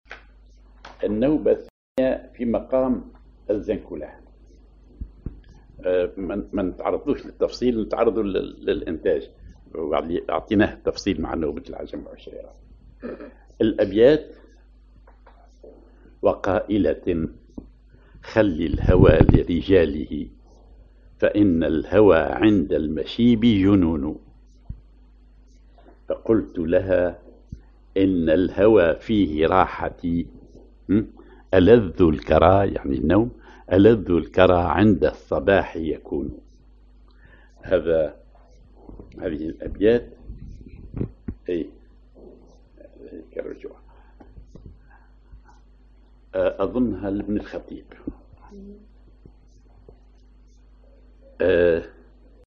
Maqam ar الزنكولاه
إيقاعات النوبة التقليدية التونسية
genre نوبة